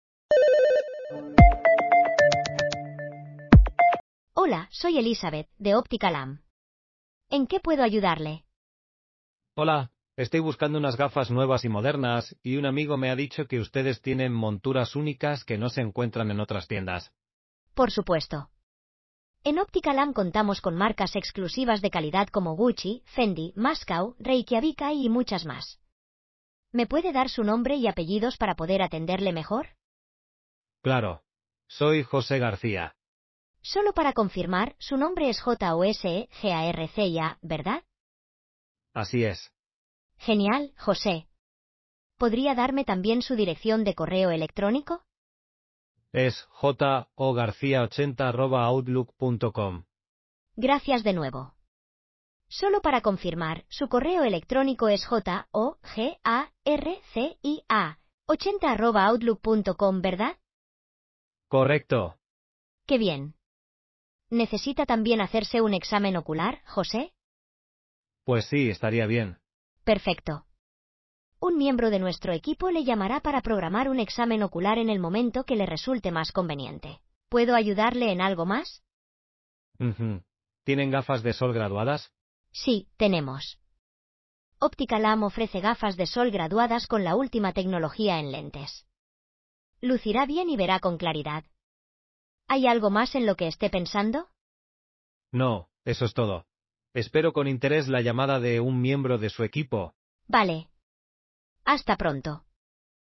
Escuche a continuación una demostración de voz AI personalizada
Recepcionista de Inteligencia Artificial